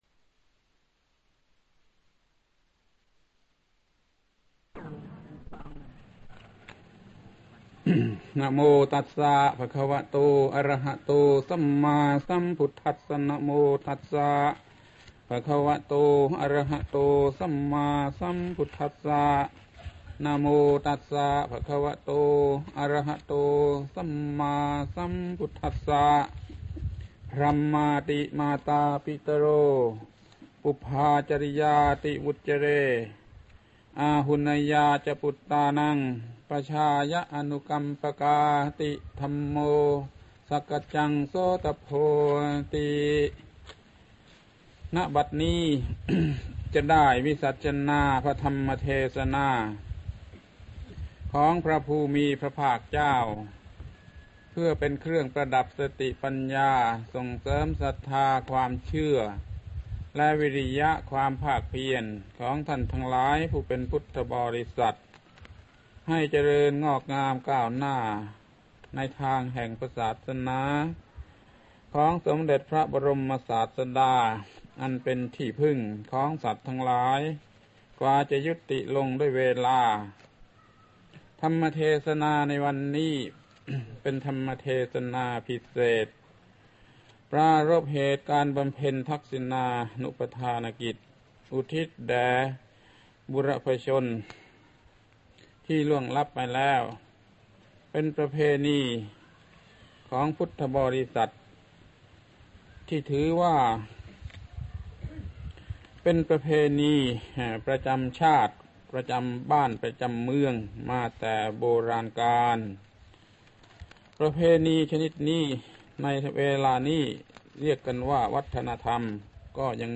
พระธรรมโกศาจารย์ (พุทธทาสภิกขุ) - เทศน์ในวันทำบุญรับ ตา ยาย